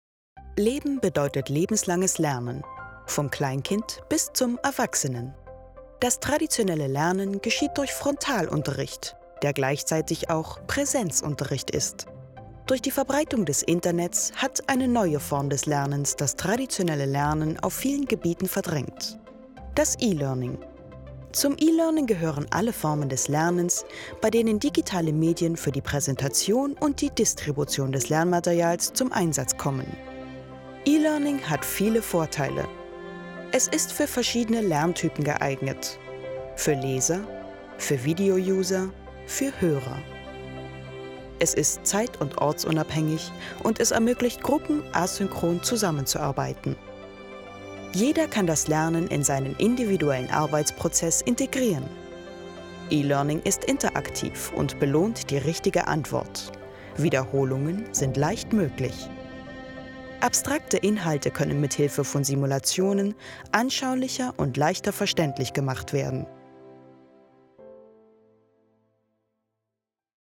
Ihre Stimme ist freundlich, sachlich/kompetent, über erzählerisch, aber auch jung, werblich, lieblich oder verführerisch.
freundlich, warm, wandelbar, frech und verführerisch
Sprechprobe: eLearning (Muttersprache):
Erklärvideo.mp3